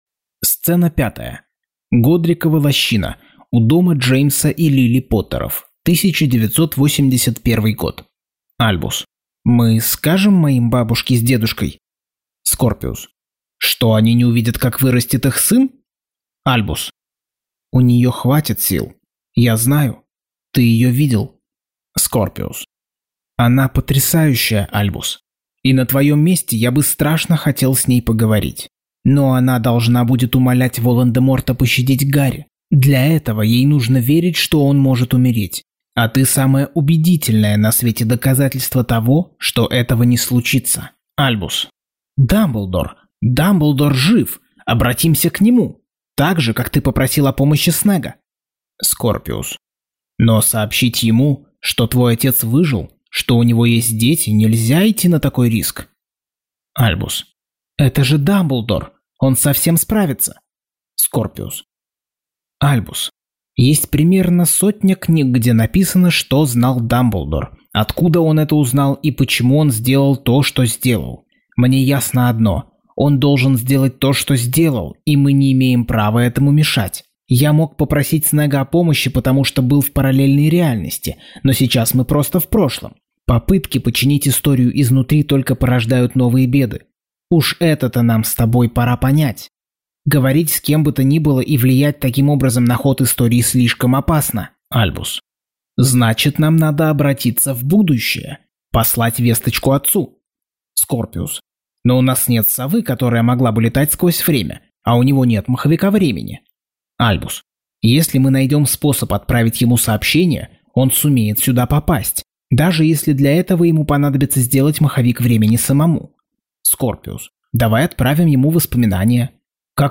Аудиокнига Гарри Поттер и проклятое дитя. Часть 57.